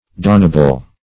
Search Result for " donable" : The Collaborative International Dictionary of English v.0.48: Donable \Do"na*ble\, a. [L. donabilis, fr. donare to donate.] Capable of being donated or given.